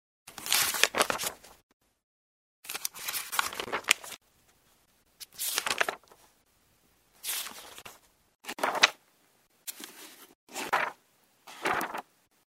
Звуки бумаги
Шорох перелистываемых страниц — третий вариант